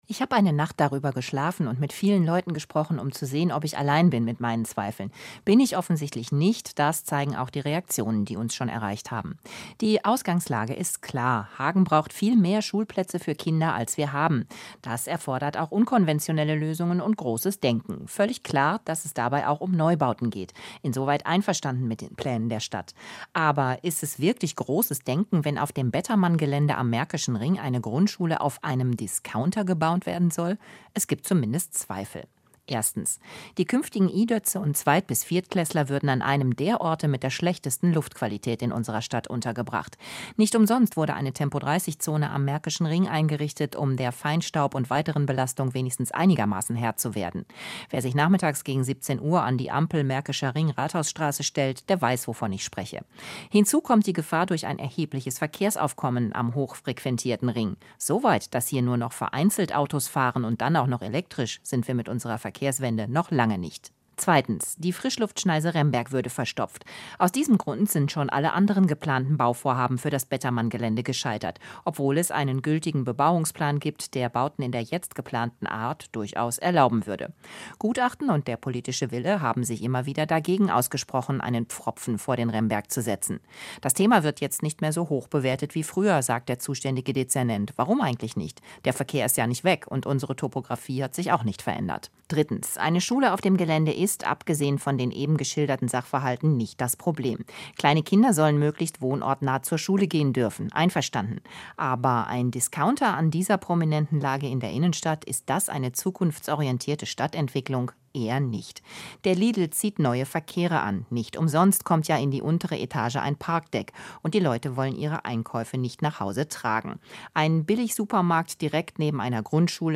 Die Bettermann-Pläne - ein Kommentar dazu